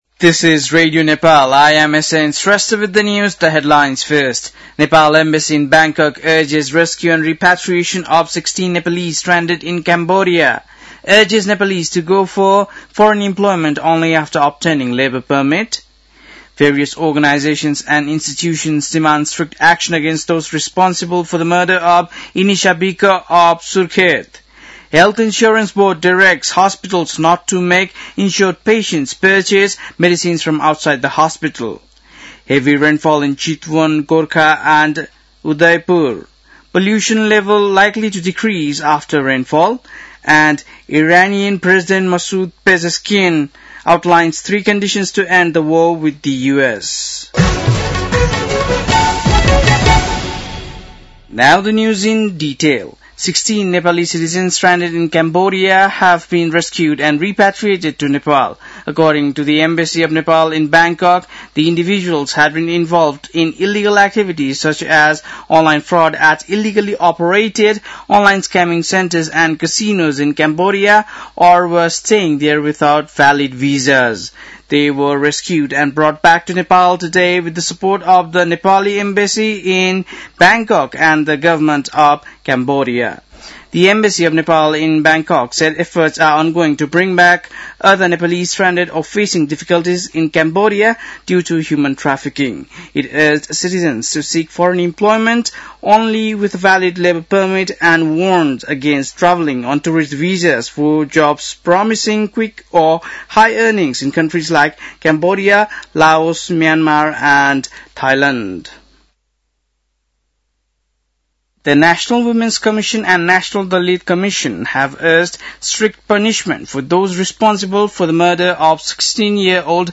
बेलुकी ८ बजेको अङ्ग्रेजी समाचार : २८ फागुन , २०८२
8-pm-english-news-11-28.mp3